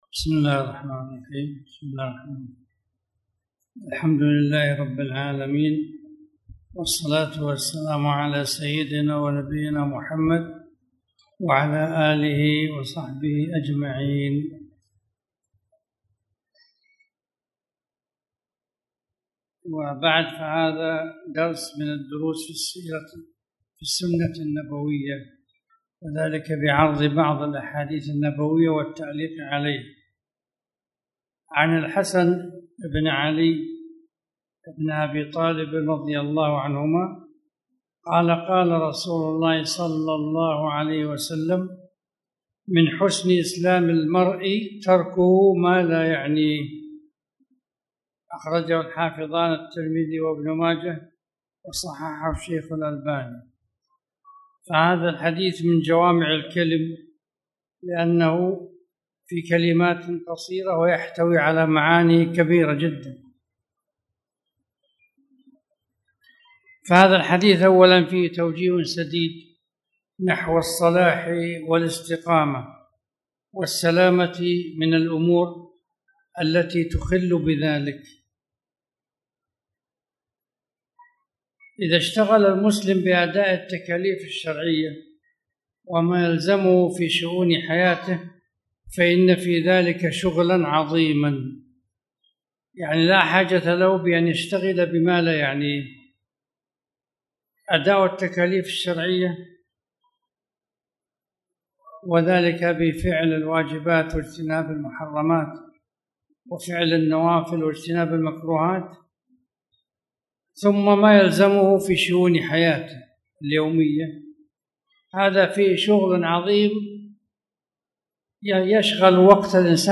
تاريخ النشر ١٨ ربيع الأول ١٤٣٨ هـ المكان: المسجد الحرام الشيخ